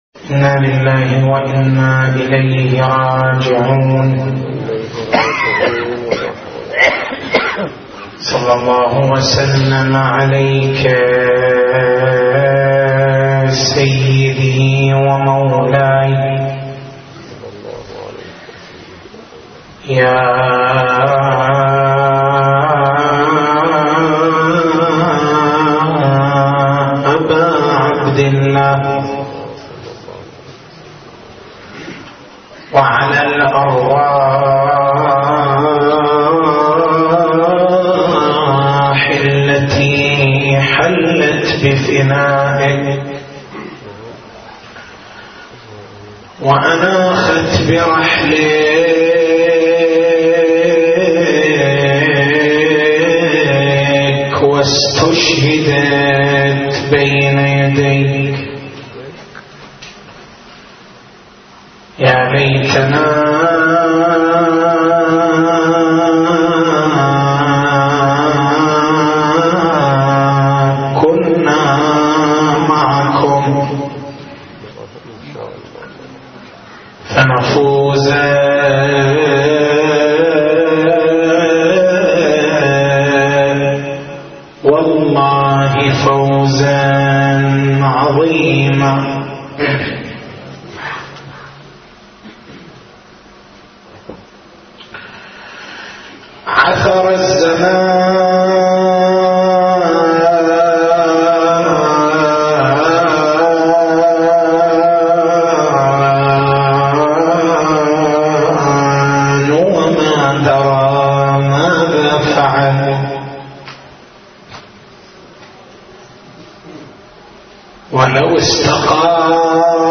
تاريخ المحاضرة: 03/01/1433 نقاط البحث: بيان حقيقة التبرّك هل المتبرّك به مصدر البركة أم مجرّد قنطرة؟